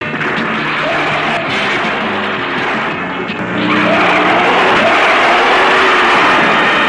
Zaninga_roar.mp3